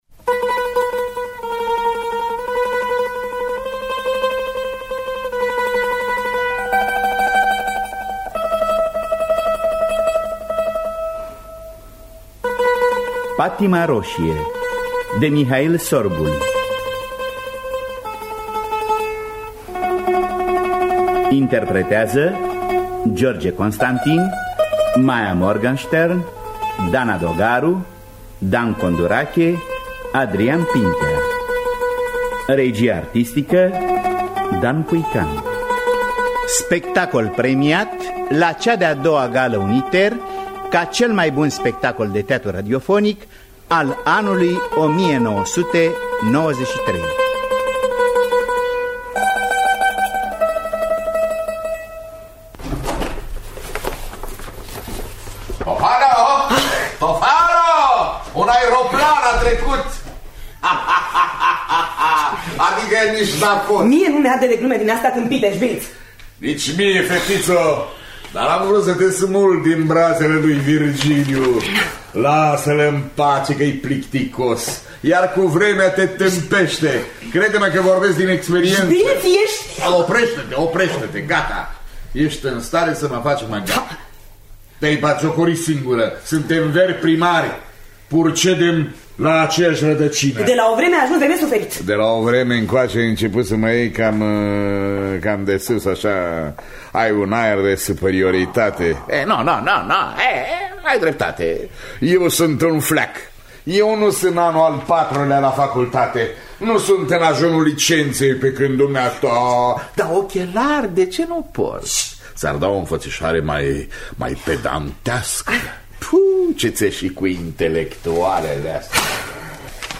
La mandolină